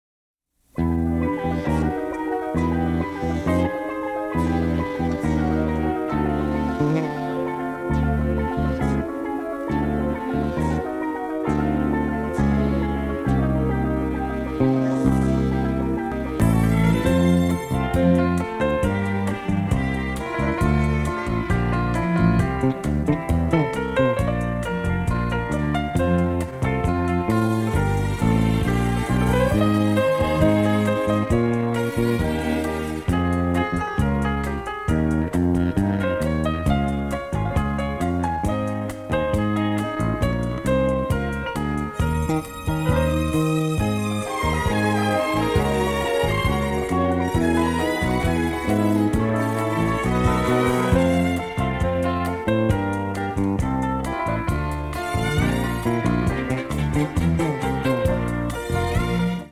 synthesizers morphed with traditional orchestral instruments